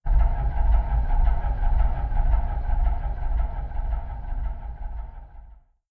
scpcb-godot/SFX/Ambient/General/Ambient14.ogg at 34e20b9e84c4340c5663a408ff2cef388eb0407a
Ambient14.ogg